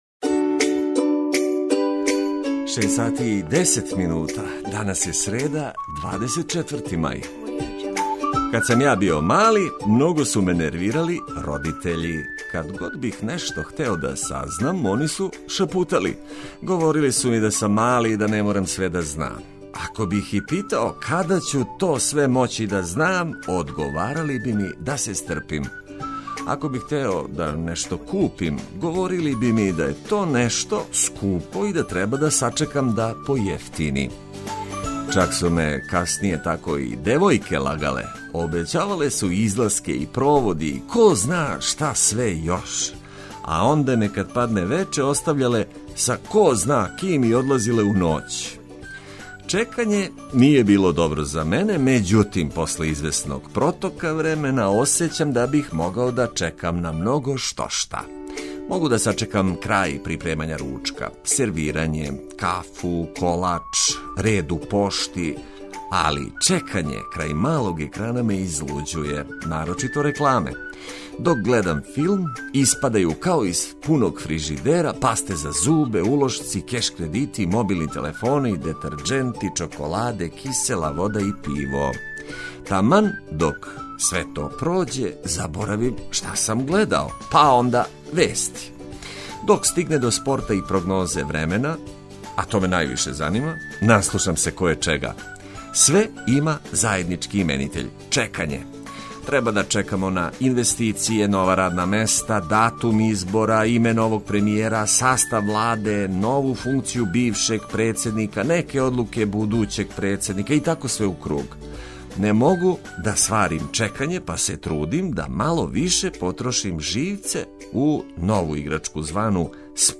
Ако бирате буђење у нашем друштву, онда вам обећавамо ведру музику, лепе али и корисне информације.